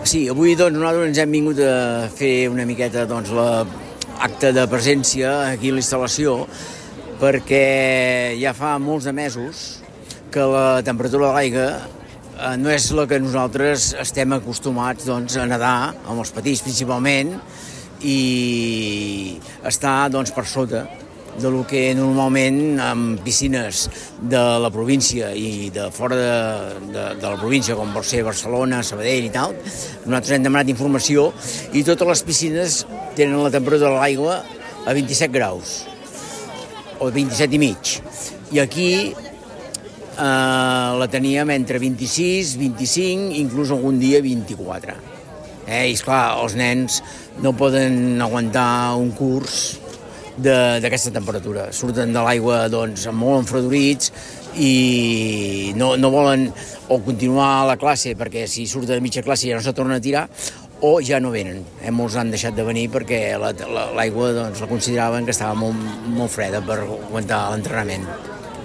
Protesta de l’AC Xaloc d’aquest dijous